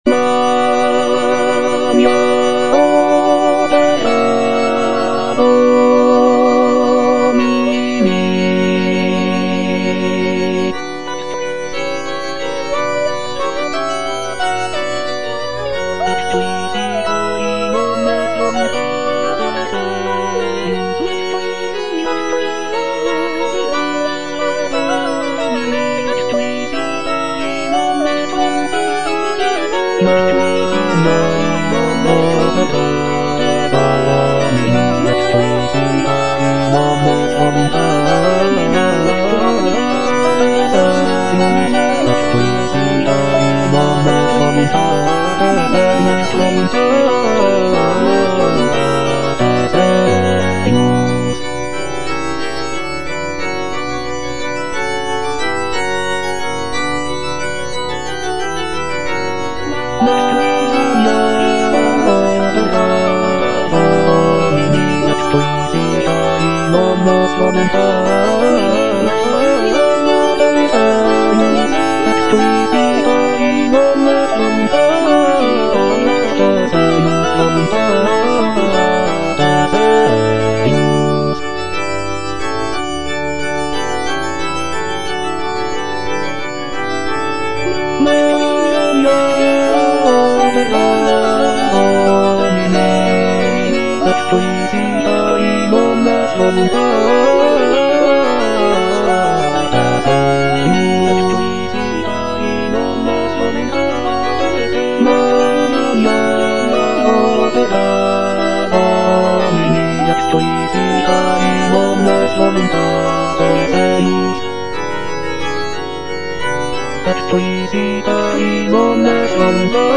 M.R. DE LALANDE - CONFITEBOR TIBI DOMINE Magna opera Domini (bass) (Emphasised voice and other voices) Ads stop: auto-stop